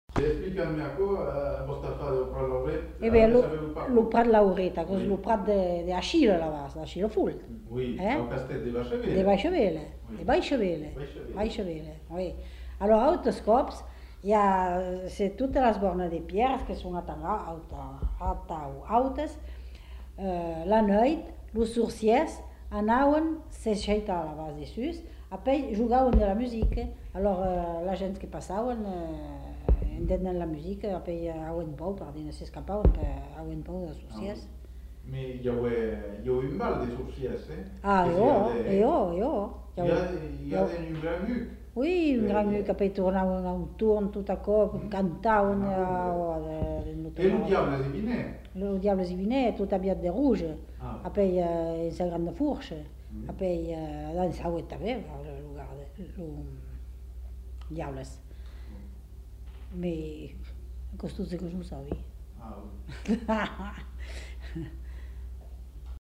Lieu : Moulis-en-Médoc
Genre : conte-légende-récit
Effectif : 1
Type de voix : voix de femme
Production du son : parlé